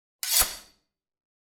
SWORD_12.wav